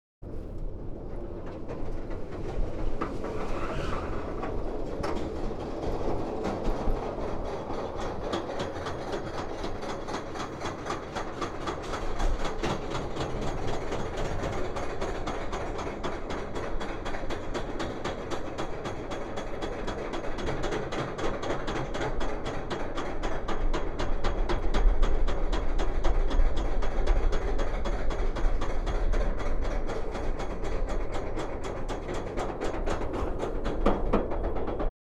ambience
Roller Coaster - Moving Past